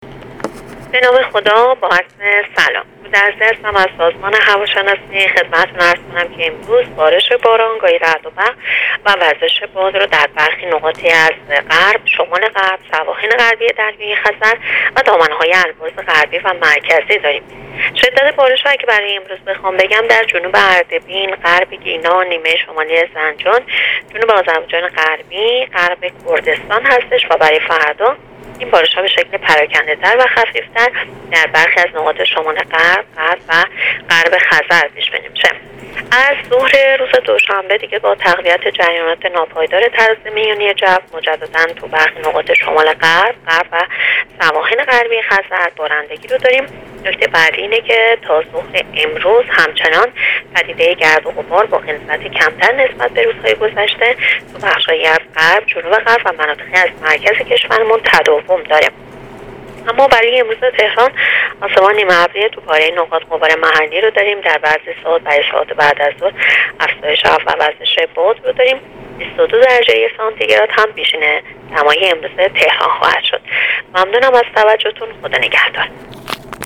در گفتگو با راديو اينترنتی پايگاه خبری